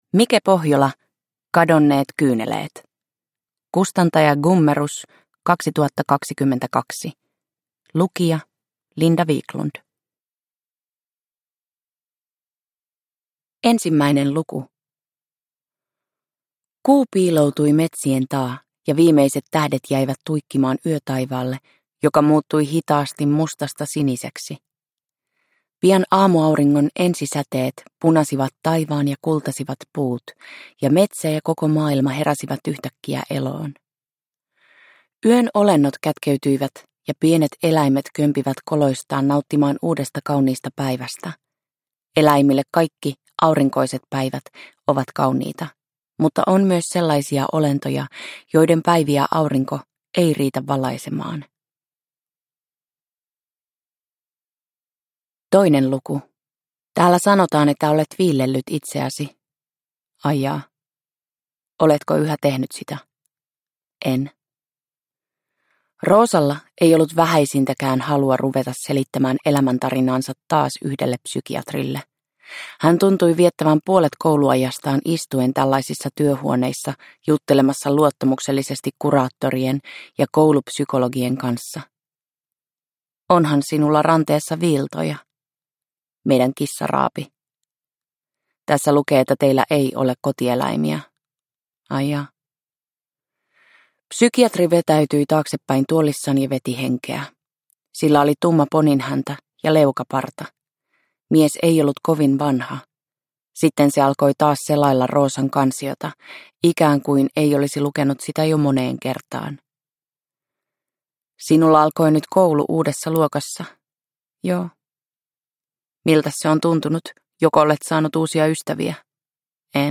Kadonneet kyyneleet – Ljudbok – Laddas ner